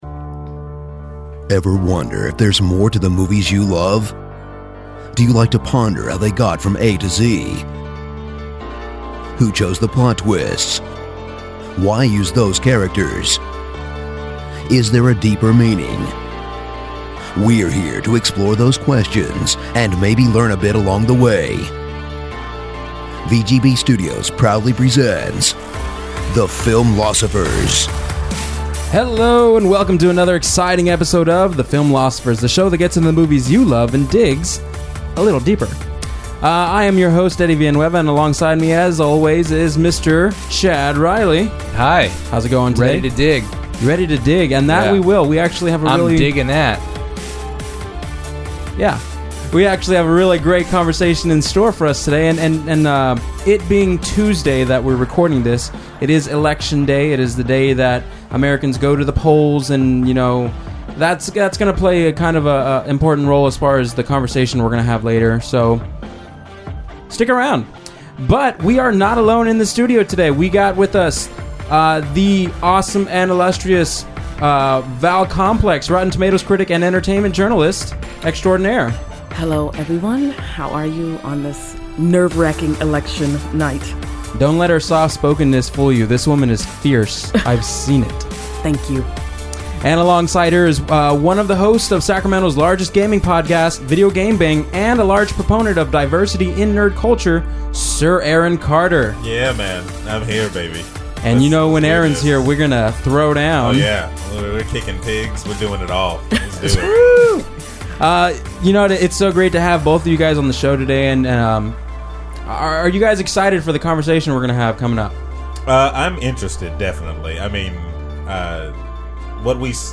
Lesson 36: Classroom Discussion - 13th